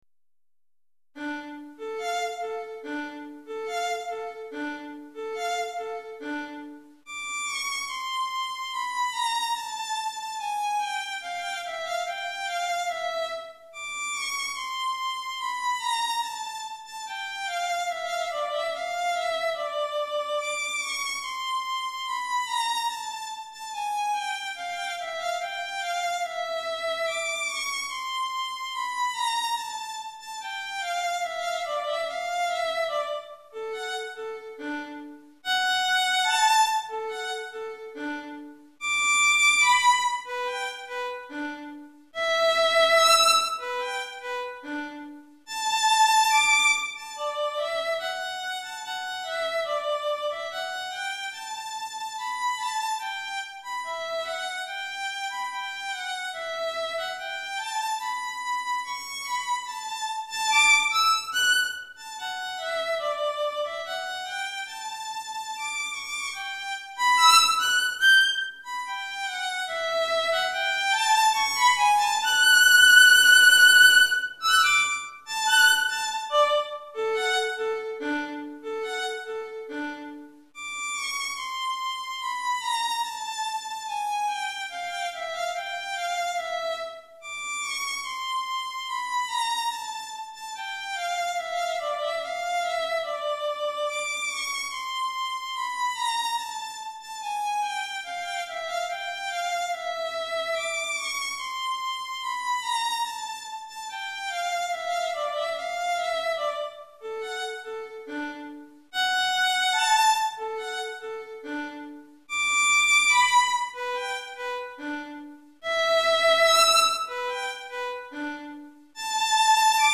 Violon Solo